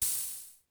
blip.ogg